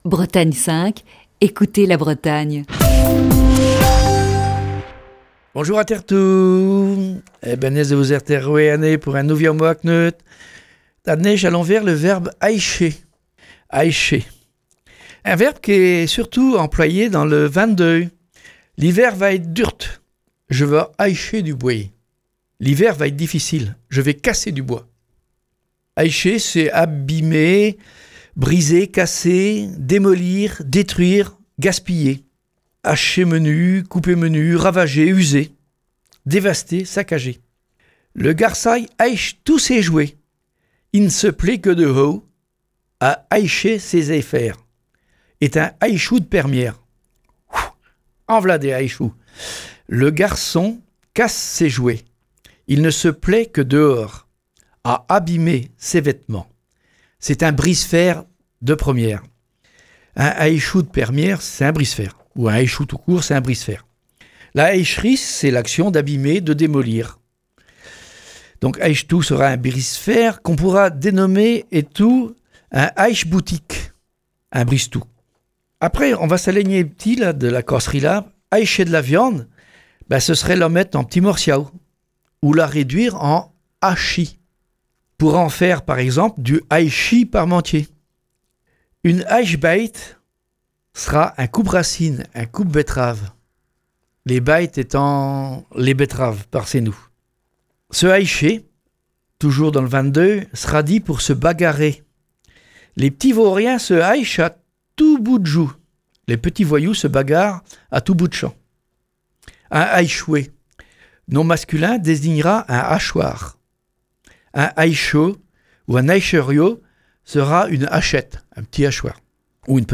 (Chronique diffusée le 29 novembre 2019).